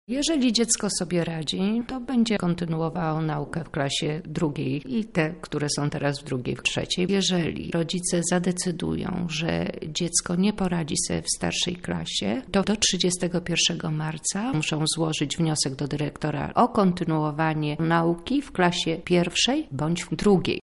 – tłumaczy Anna Szczepińska, wicekurator Oświaty.